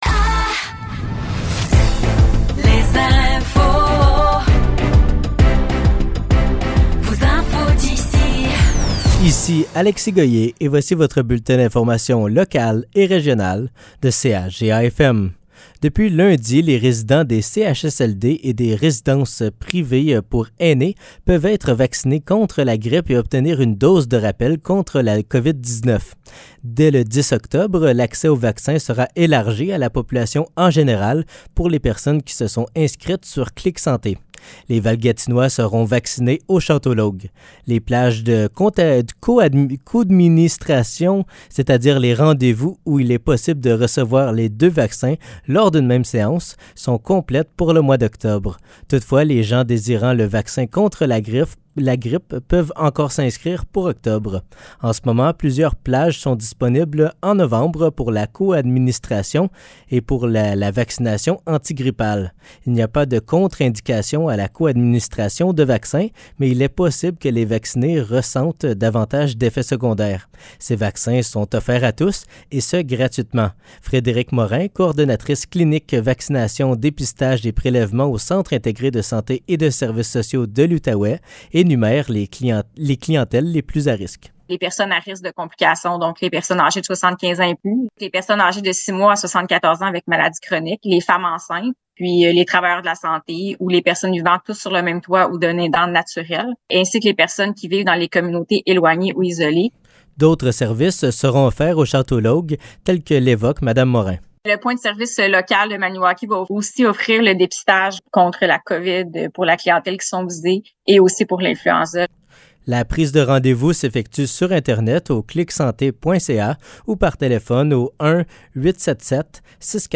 Nouvelles locales - 3 octobre 2023 - 15 h